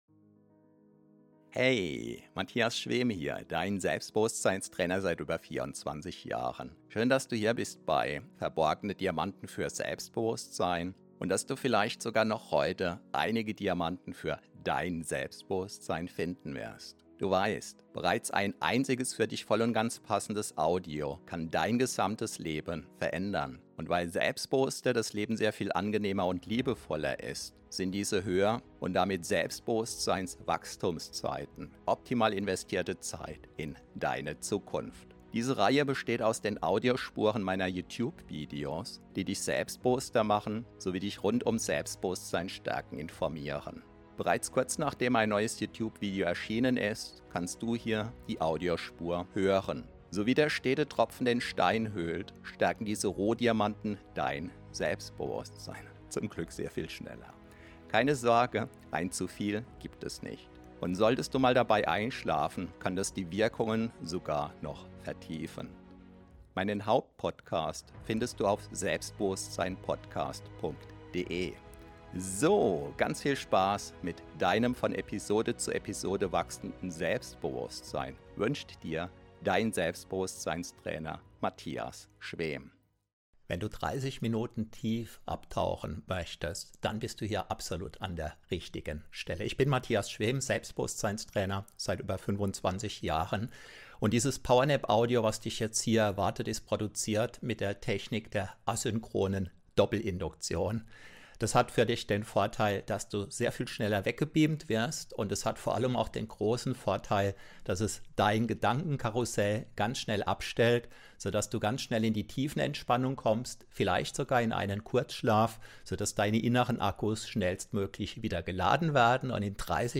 Power Nap 30 min ACHTUNG: Extrem STARK asynchrone Doppelinduktion! Power Napping deutsch NSDR ~ Verborgene Diamanten Podcast [Alles mit Selbstbewusstsein] Podcast